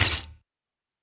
sfx_button.wav